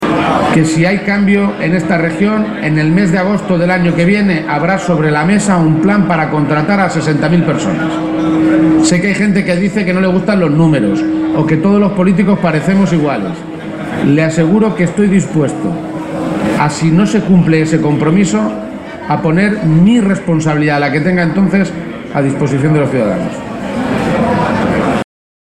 Page se pronunciaba de esta manera en su comparecencia ante los medios de comunicación, en Albacete, pocos minutos antes del acto de entrega de los Premios Pablo Iglesias que otorga la agrupación local socialista de la capital albaceteña.